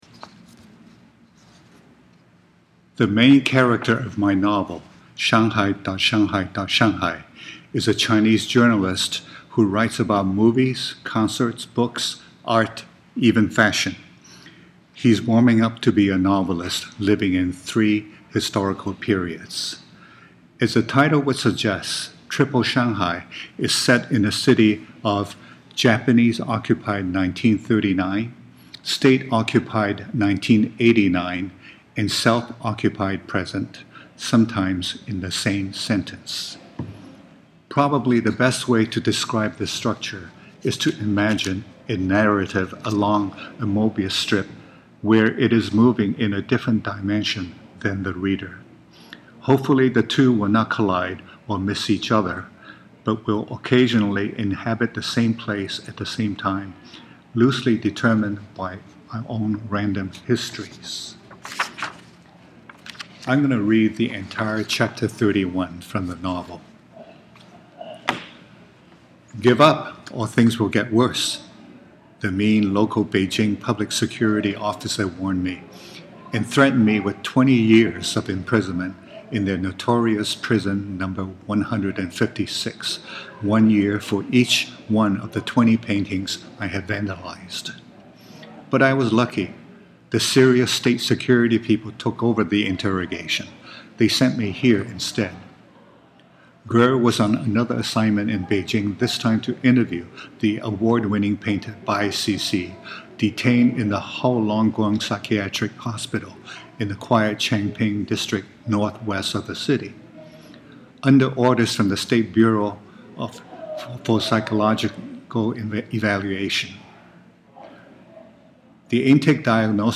A reading from shanghai.shanghai.shanghai | Washington State Magazine | Washington State University